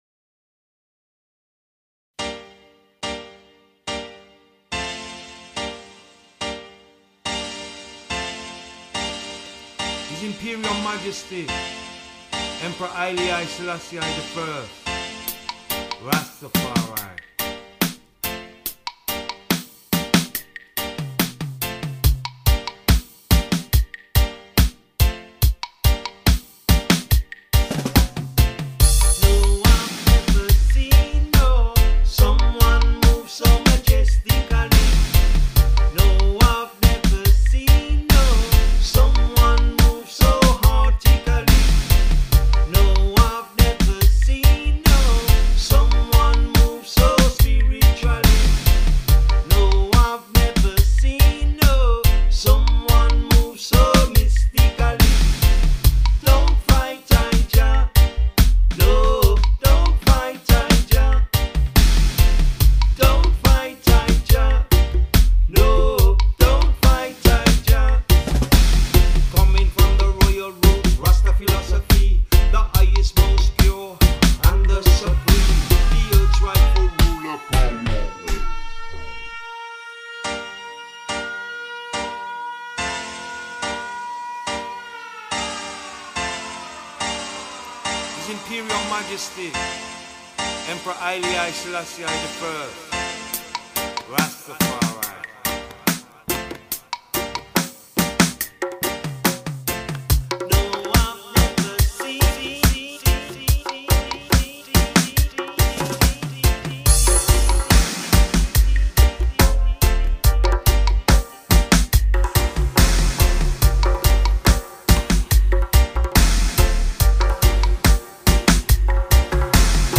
dubmix
drums